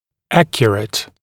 [‘ækjərət][‘экйэрэт]точный, правильный, достоверный